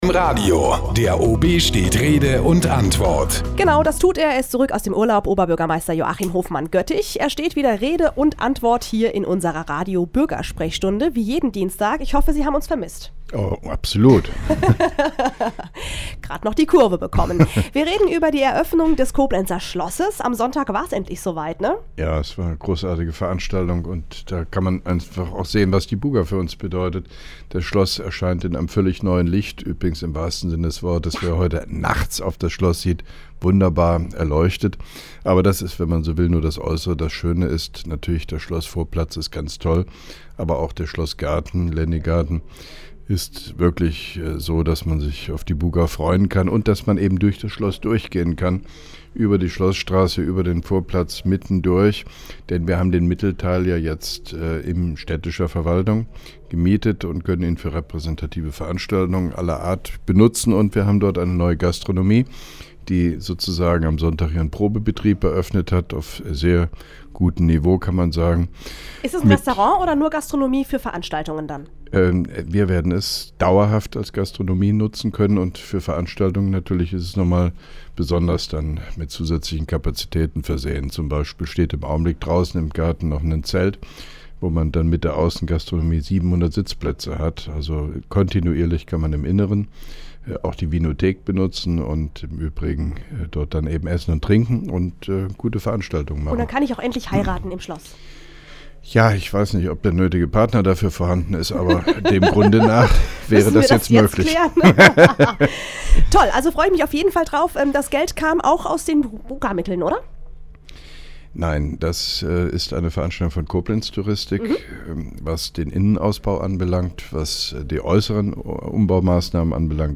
(2) Koblenzer Radio-Bürgersprechstunde mit OB Hofmann-Göttig 05.04.2011